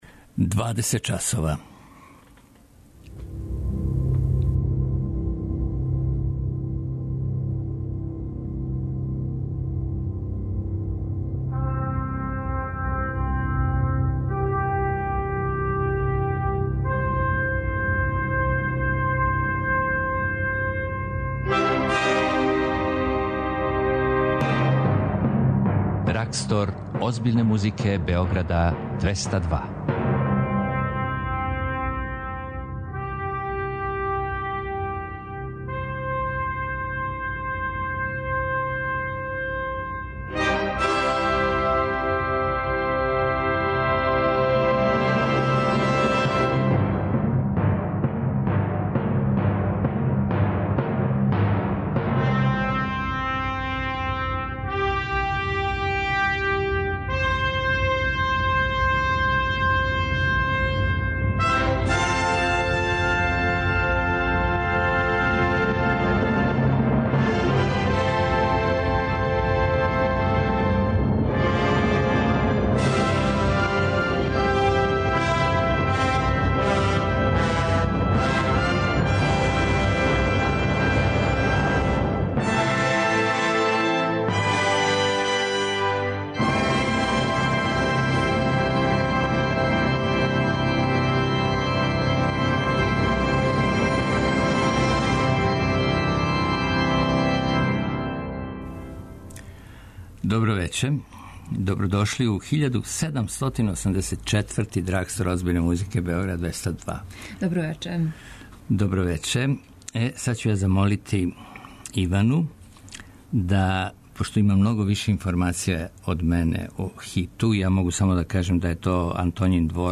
Култна емисија Београда 202 која промовише класичну музику.